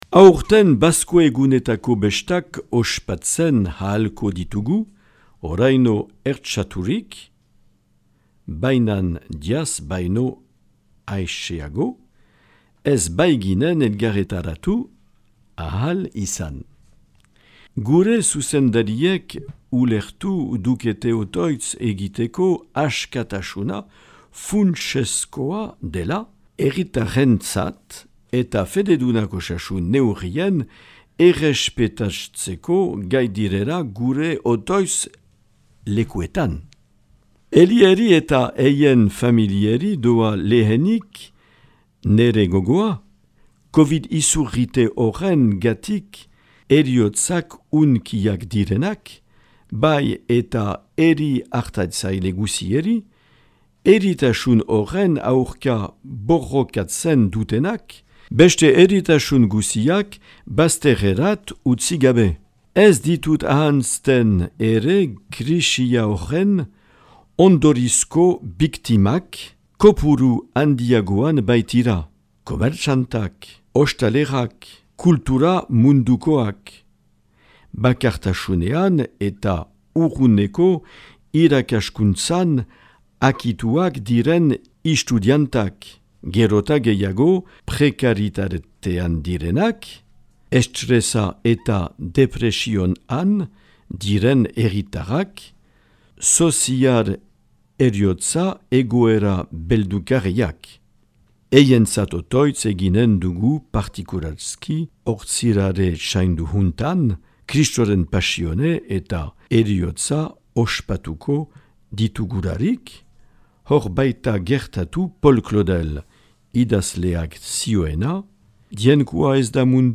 Marc Aillet jaun apezpikuaren 2021. Bazko mezua.